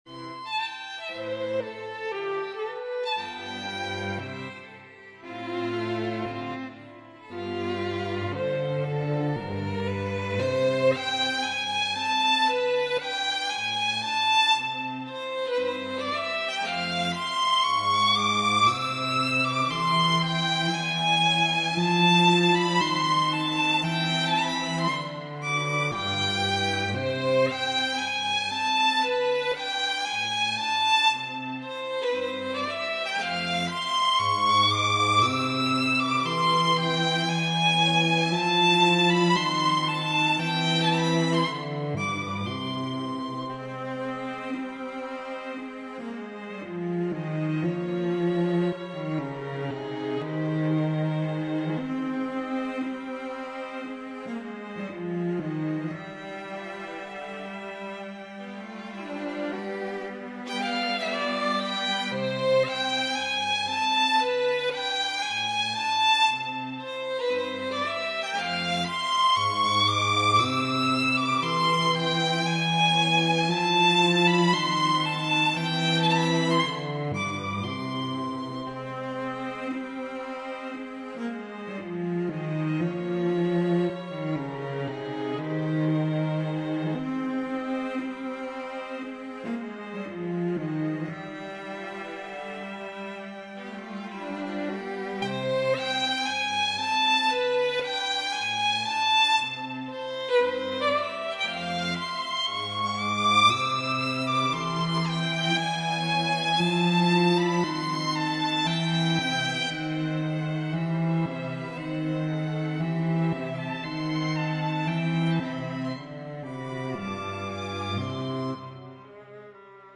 Film music, Pop/Contemporary, Wedding